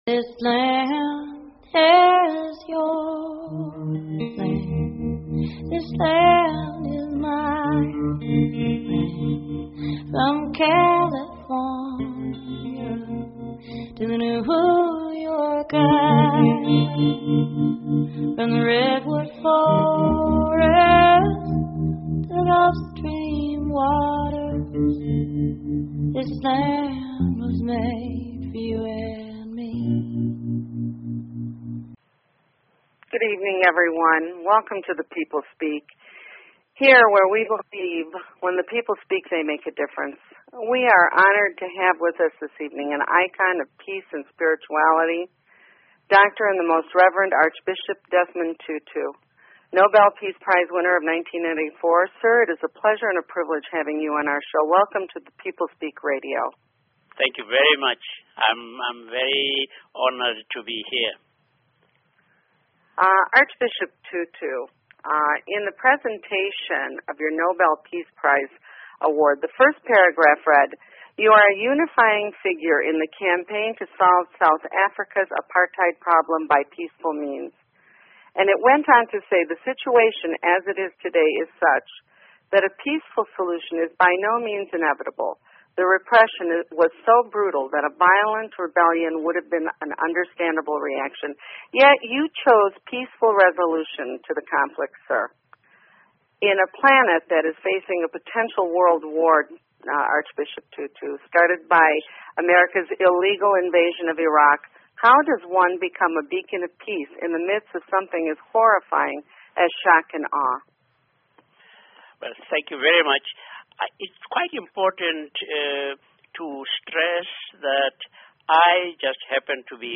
Talk Show Episode, Audio Podcast, The_People_Speak and Archbishop Desmond Tutu on , show guests , about , categorized as Education,News,Politics & Government
Guest, Desmond Tutu